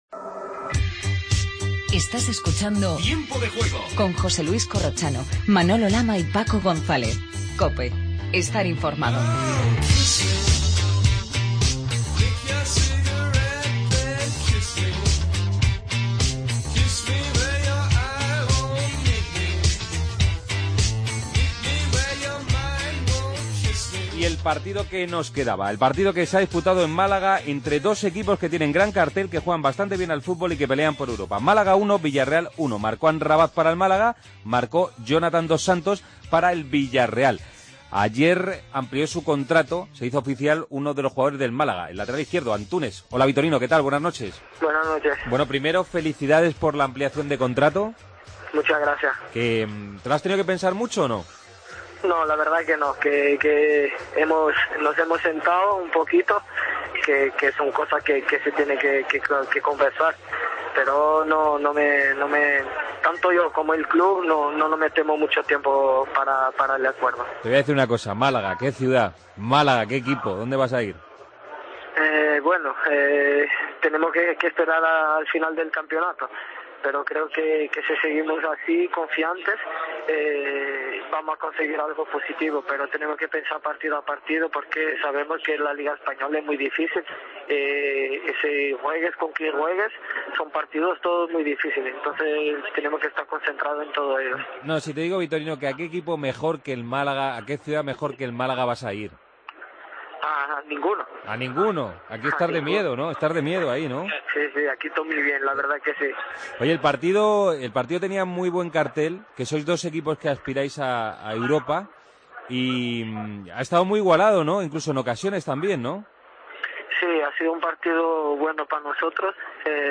Redacción digital Madrid - Publicado el 11 ene 2015, 01:56 - Actualizado 14 mar 2023, 01:10 1 min lectura Descargar Facebook Twitter Whatsapp Telegram Enviar por email Copiar enlace Resto de noticias de fútbol. Entrevista al jugador del Málaga, Antunes.